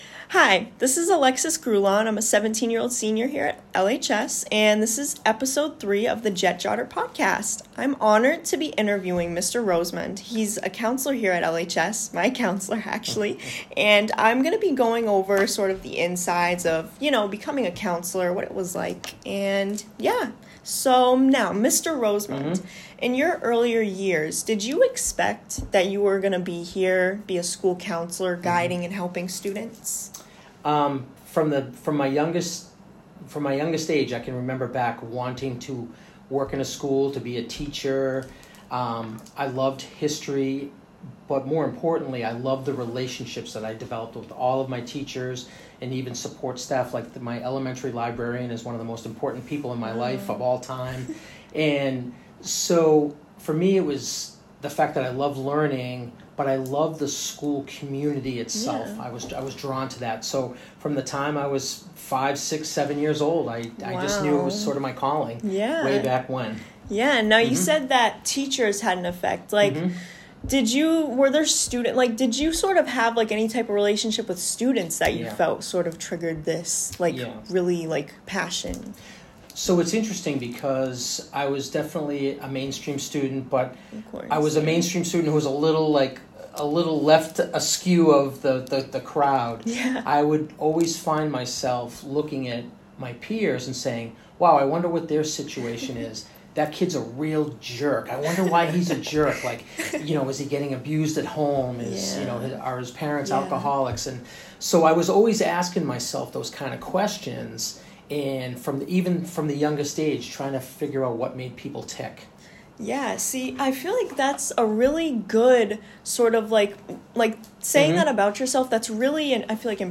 An interview with a guidance counselor from Longmeadow Highschool.